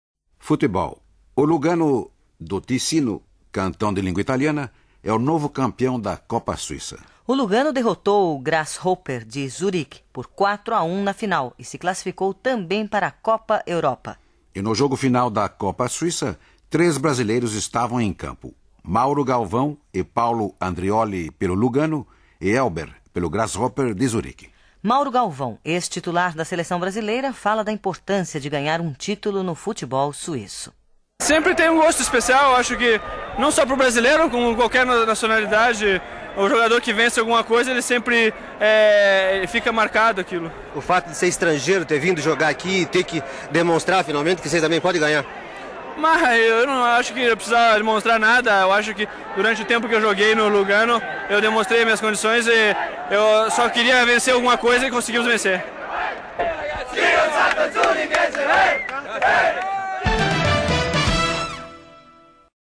(Arquivo da Rádio Suíça Internacional).